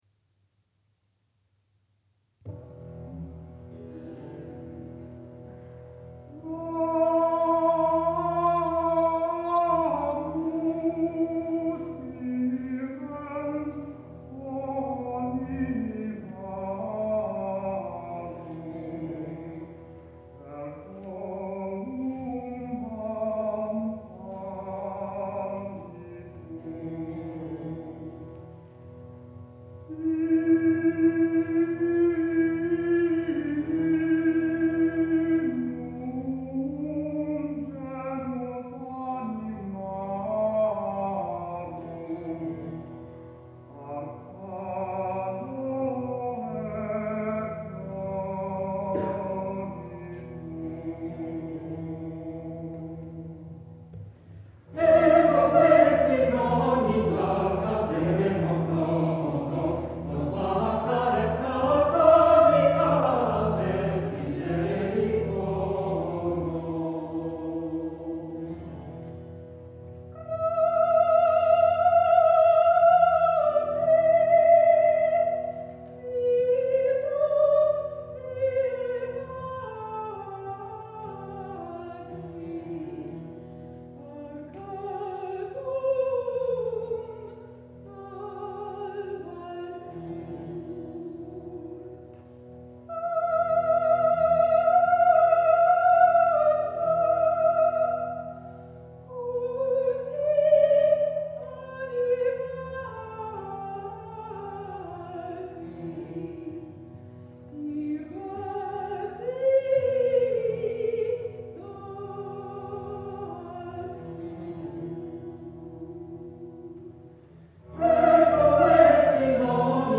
The amazing variety of forms and styles contained in ‘Piae cantiones” is shown in this very Phrygian carol .